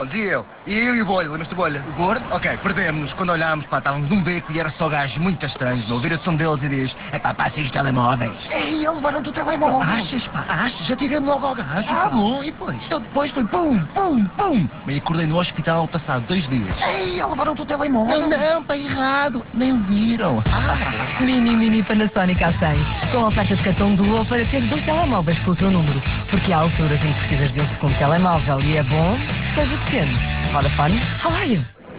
(oiça aqui o spot) está a passar na RFM, Cidade FM e RC, tendo desde a estreia do spot até ao dia 26 de Agosto sido investidos para 539 inserções, 181 894 euros, a preço tabela.